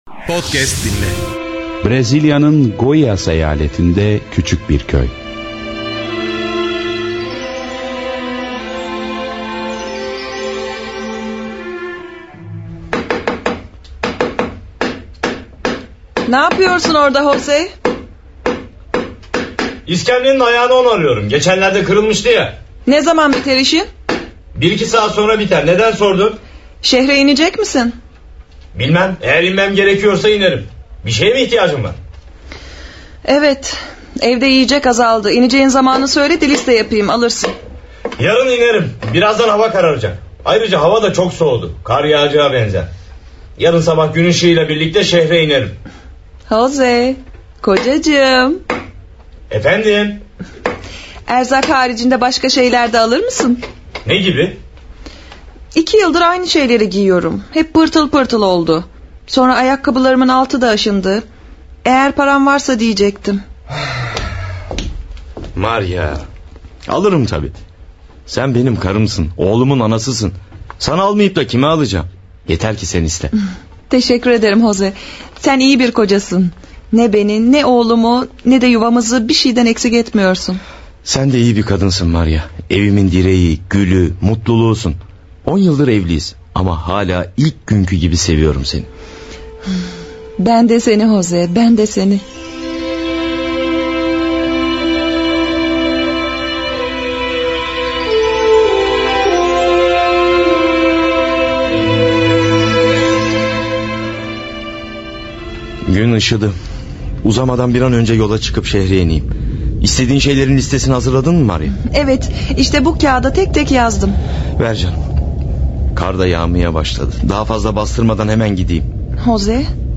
Radyo Tiyatrosu